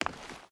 Footsteps / Stone / Stone Run 4.ogg
Stone Run 4.ogg